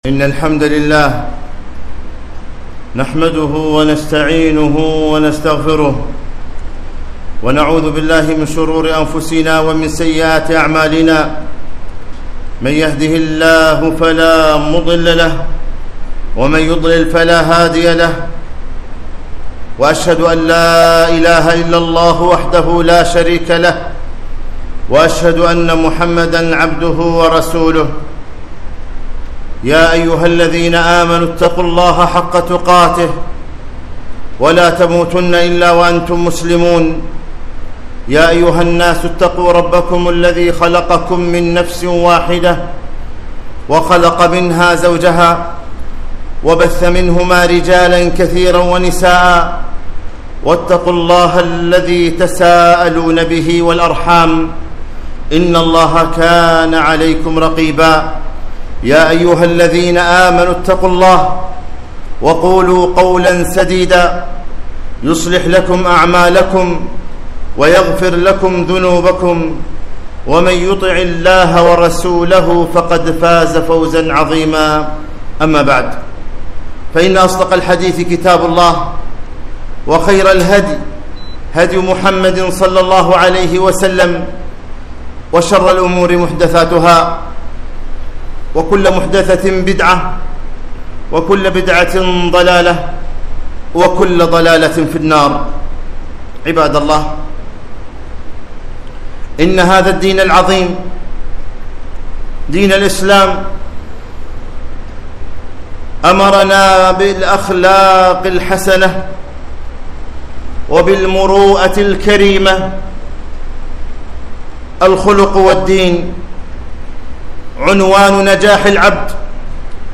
خطبة - خالق الناس بخلق حسن